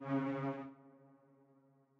Buildup_2.wav